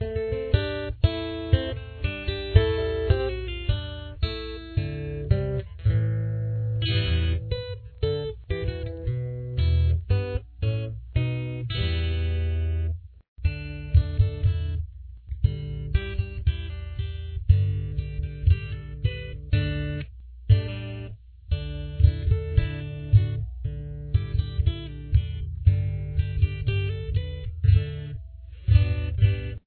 • Key Of: D
• Instruments: Acoustic Guitar
Full Intro